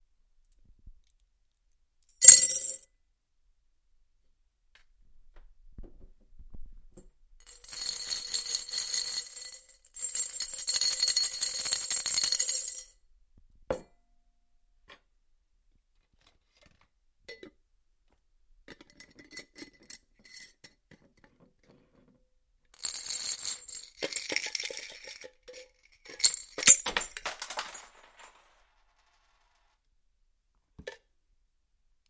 Звук внезапно разбившейся банки с монетами во время записи.
zvuk_vnezapno_razbivshejsya_banki_s_monetami_vo_vremya_zapisi._1bd.mp3